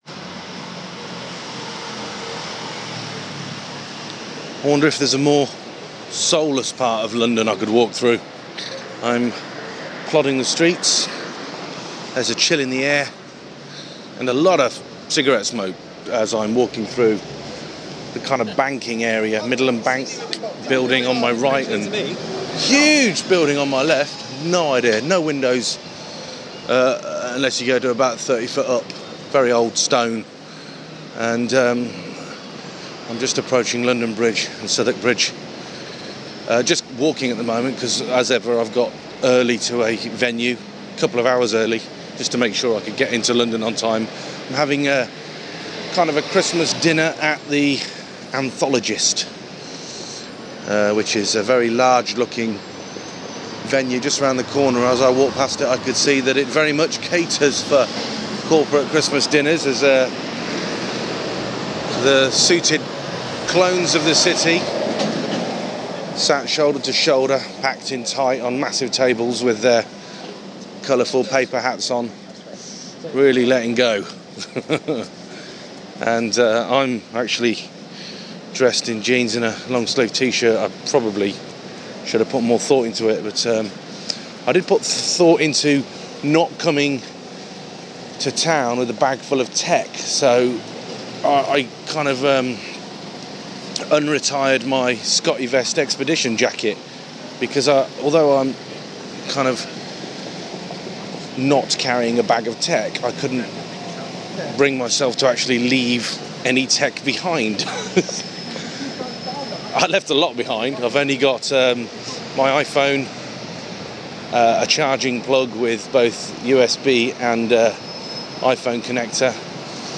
Walking the streets of London at night in the cold with nothing but a jacket full of tech.